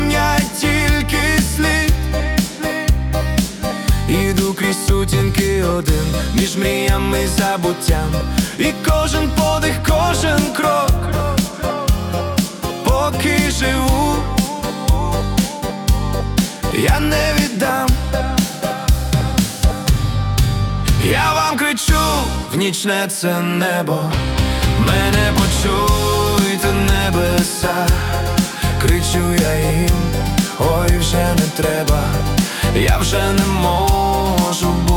Жанр: Альтернатива / Русские